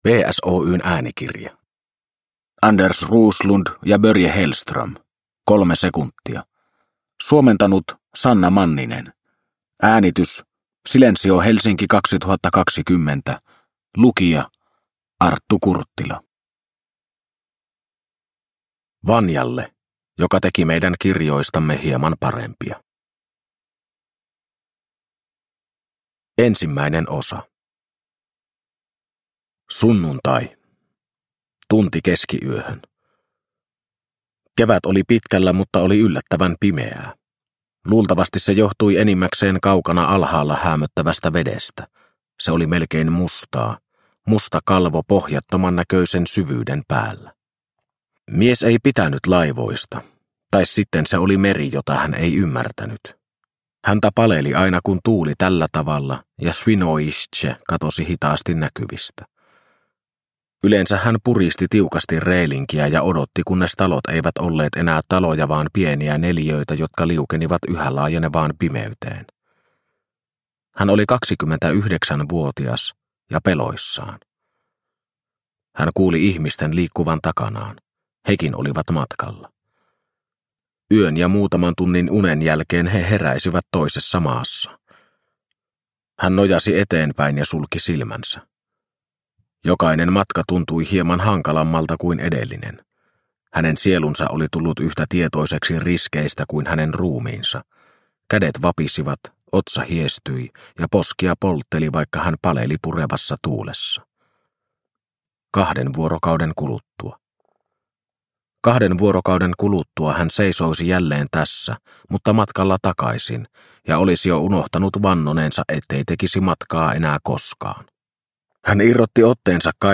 Kolme sekuntia – Ljudbok – Laddas ner